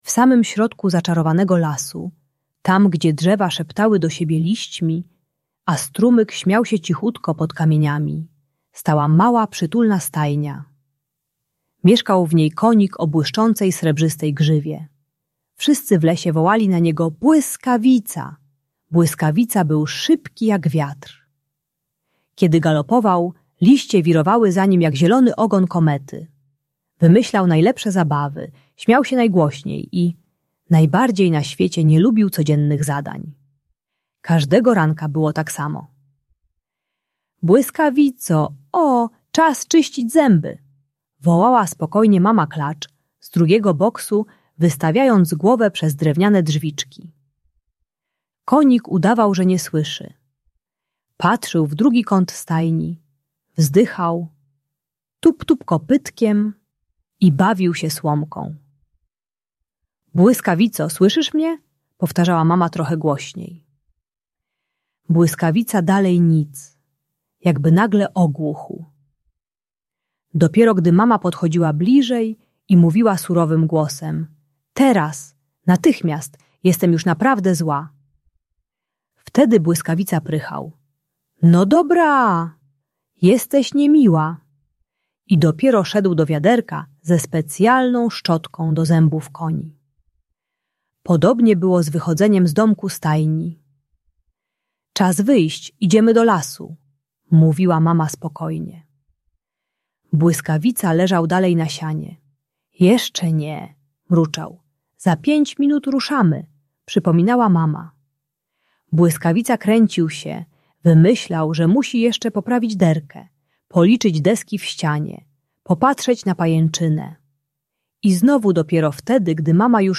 Błyskawica: Bajka o koniku i jego przygodach - Bunt i wybuchy złości | Audiobajka
Historia konika Błyskawicy uczy techniki STOP-ODDECH-PRAWDA, pomagającej dziecku opanować bunt i złość zanim wybuchnie krzykiem. Audiobajka o radzeniu sobie z frustracją i współpracy z rodzicami.